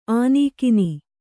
♪ ānīkini